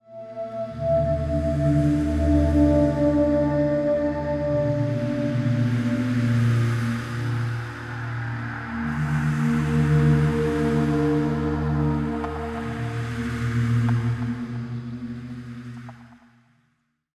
ambience.wav